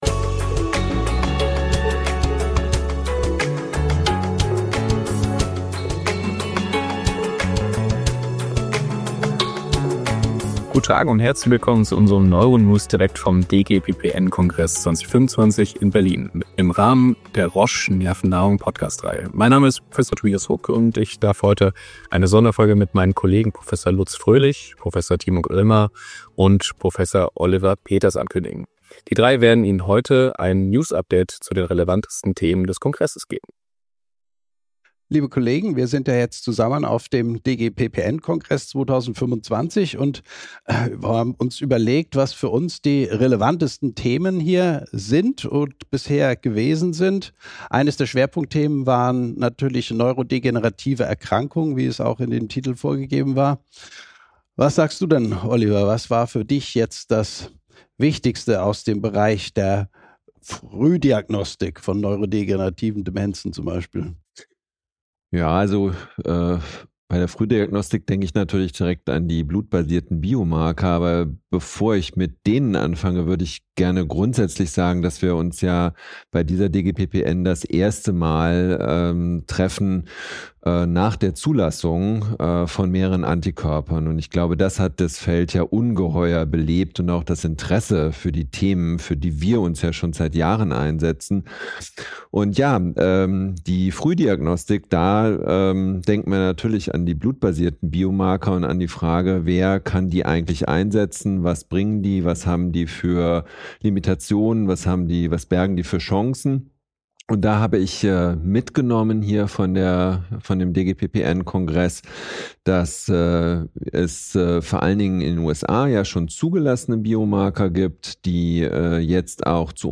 live vom DGPPN, dem Kongress der Deutschen Gesellschaft für Psychiatrie und Psychotherapie, Psychosomatik und Nervenheilkunde. Sie fokussieren sich auf die Highlights im Bereich neurodegenerativer Erkrankungen und bereiten die Ergebnisse verständlich auf.